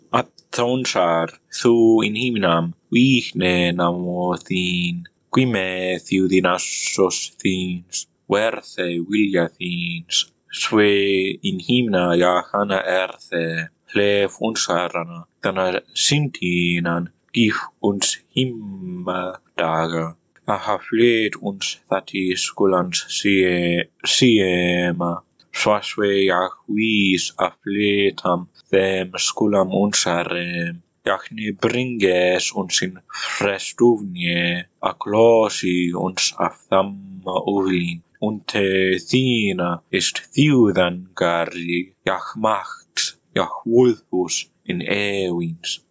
Sample text in Gothic (The Lord's Prayer)